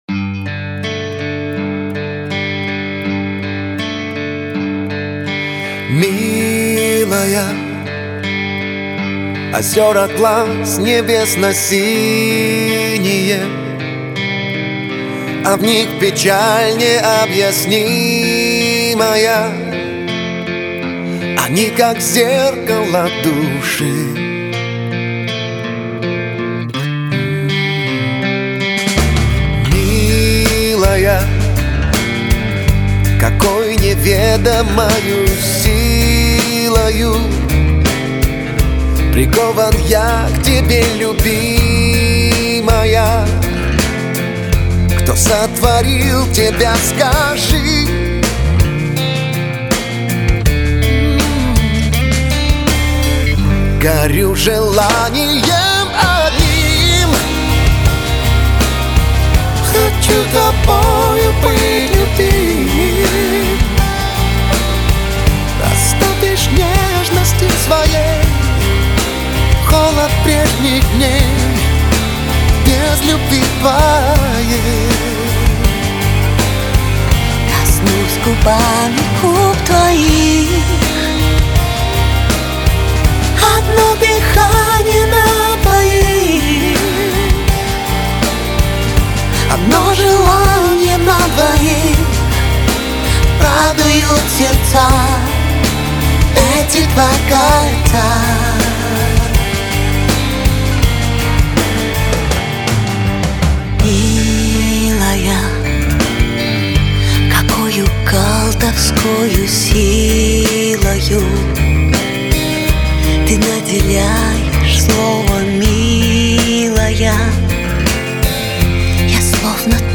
Приятные голоса.